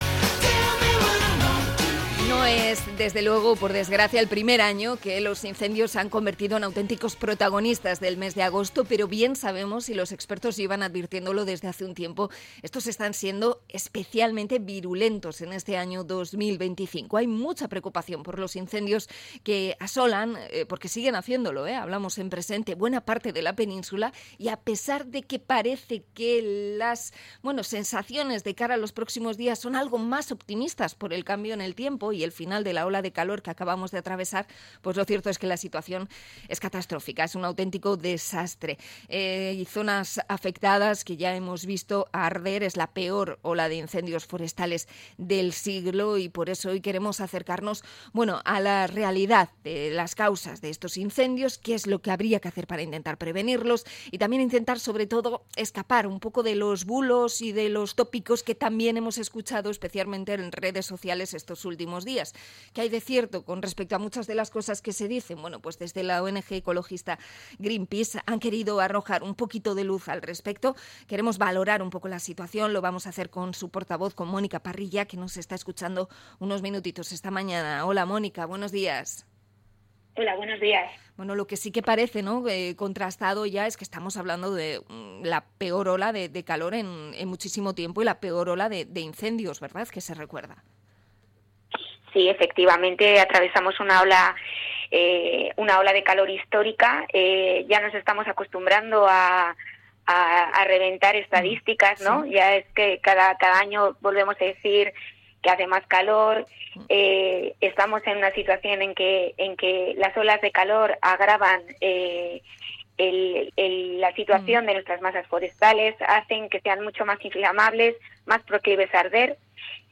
Entrevista a Greenpeace por los incendios de este verano 2025